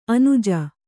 ♪ anuja